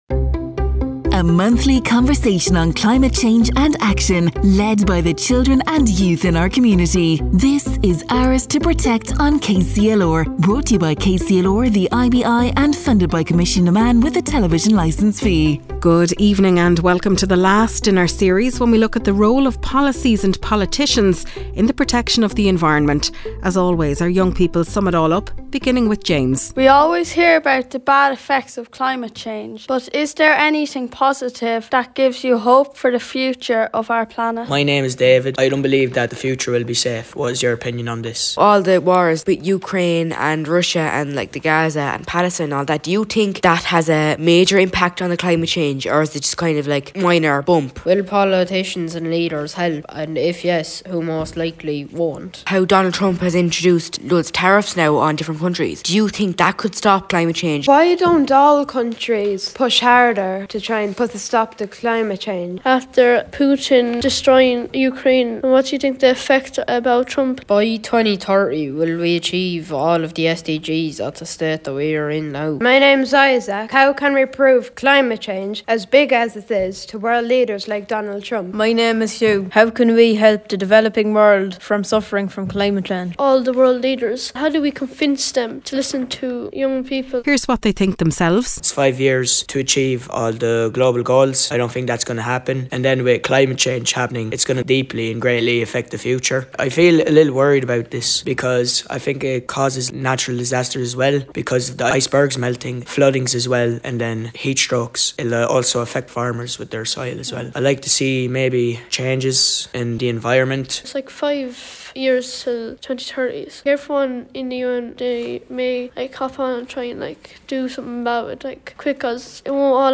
As part of this year’s series KCLR installed a recording device on the grounds of a local school.